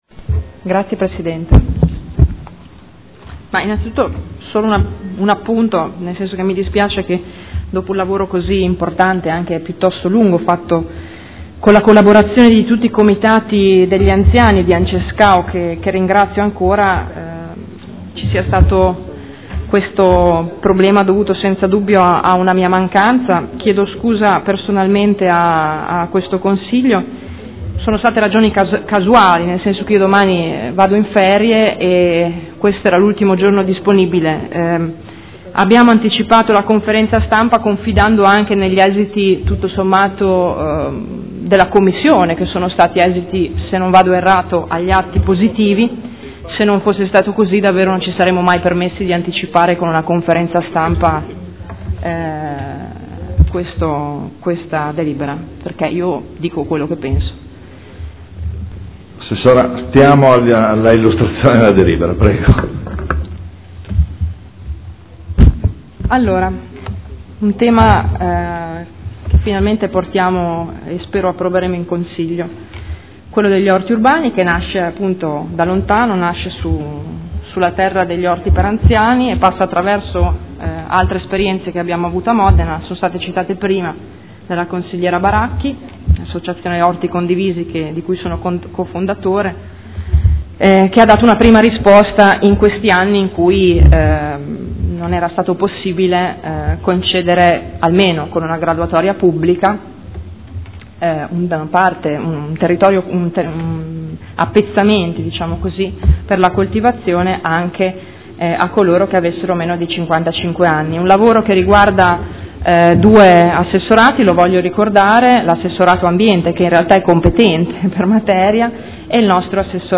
Seduta del 23/07/2015 Regolamento per la concessione in uso ad associazioni e privati di aree per la realizzazione di orti urbani - Approvazione